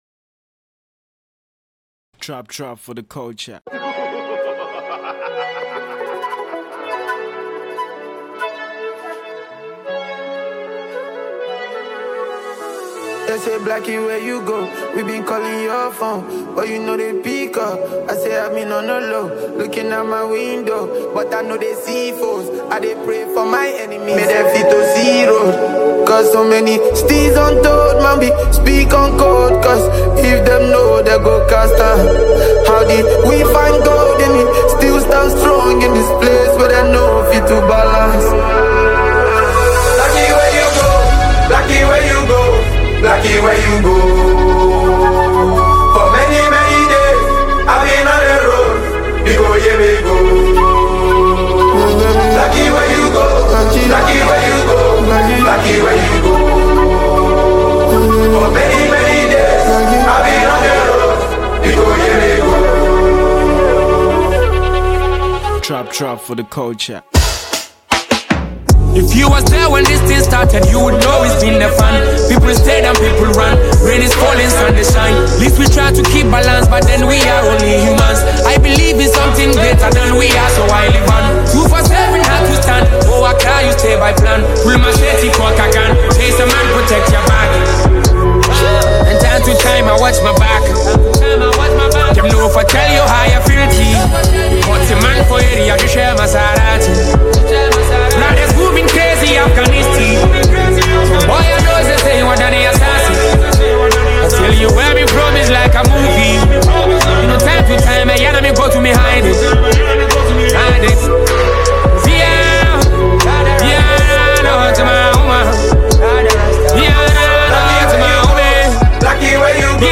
blending Ghanaian music and Afrobeat vibes.